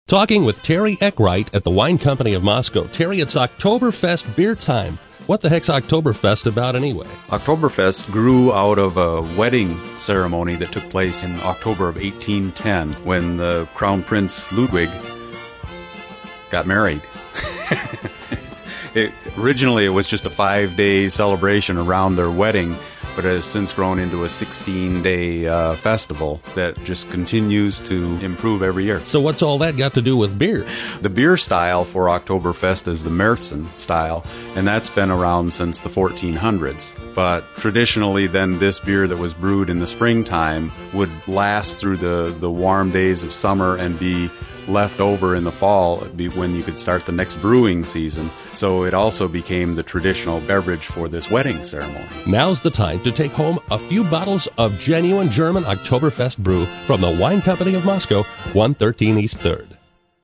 Here’s a commercial typical of his approach: